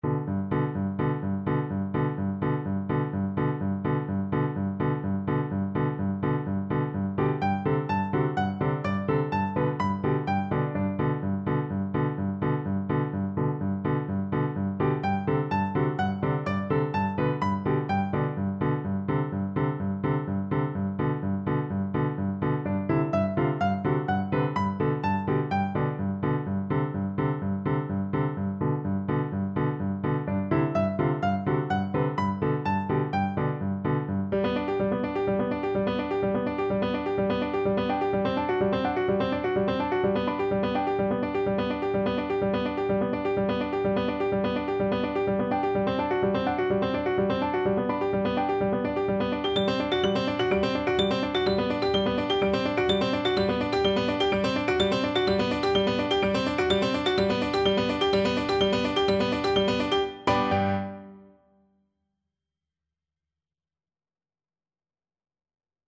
Allegro moderato (=126) (View more music marked Allegro)
4/4 (View more 4/4 Music)
Classical (View more Classical Cello Music)